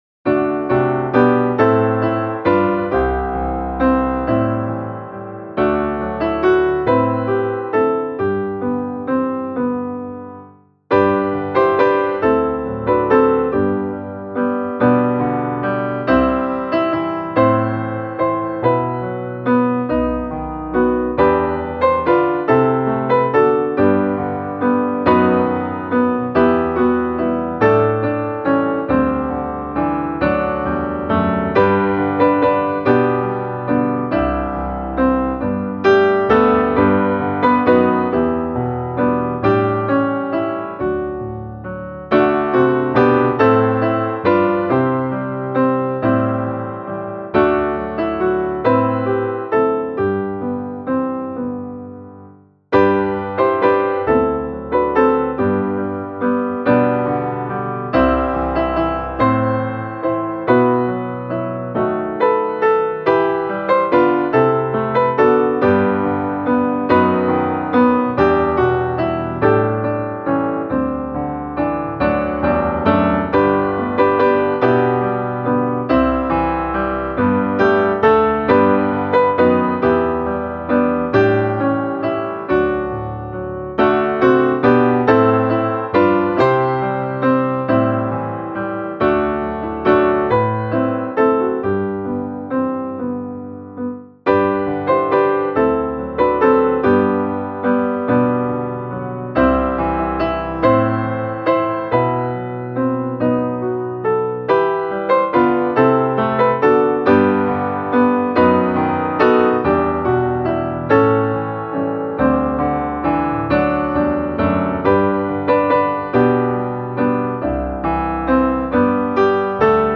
Löftena kunna ej svika - musikbakgrund
Musikbakgrund Psalm